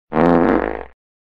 Furzgeräusche Klingelton Für Android (Mp3) Und IPhone